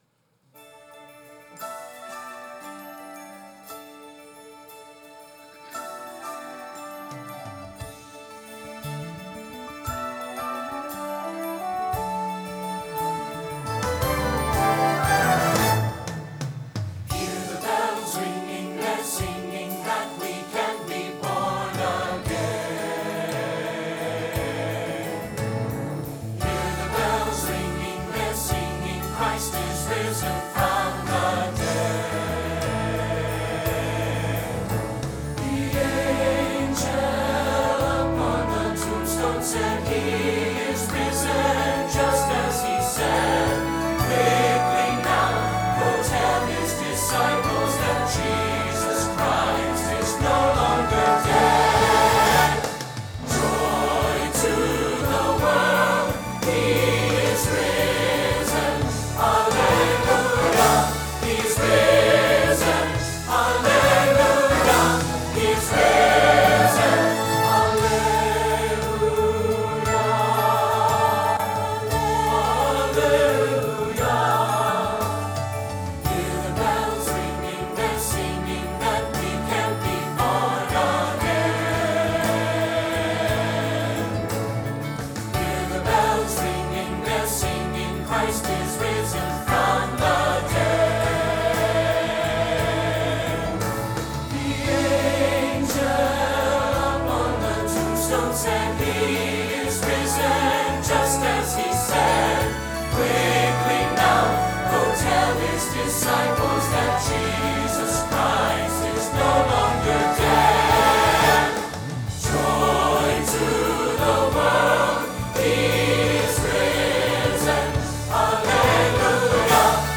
Sunday Morning Music
Celebration Choir